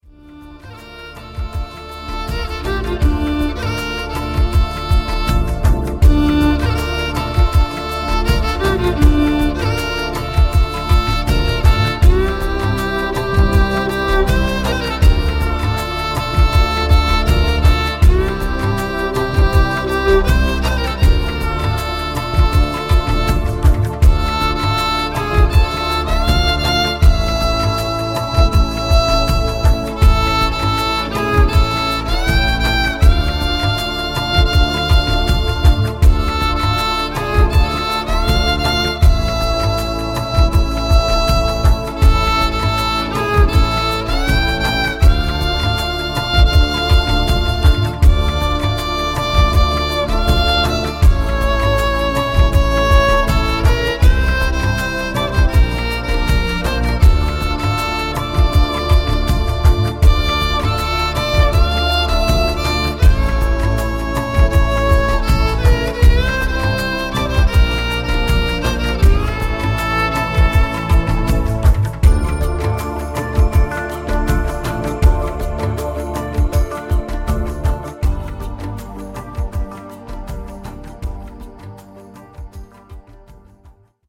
world fusion, new age music
Genre: World Fusion